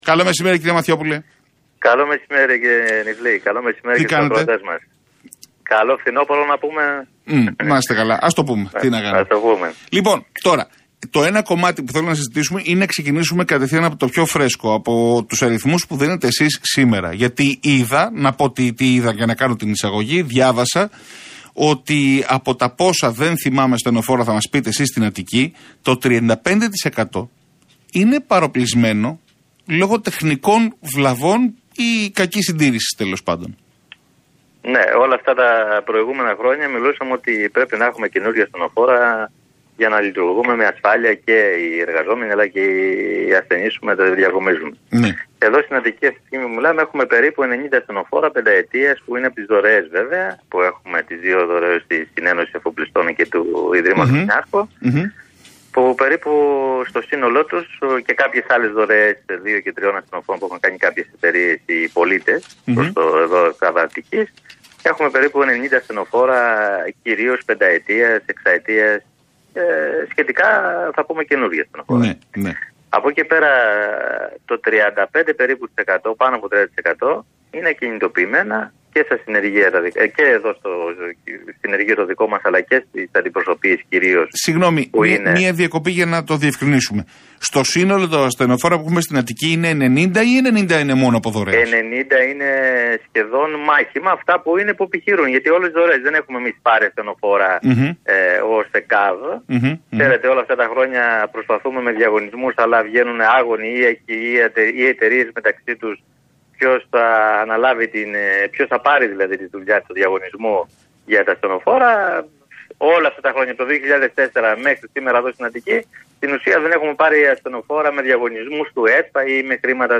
στον ραδιοφωνικό σταθμό Realfm 97,8.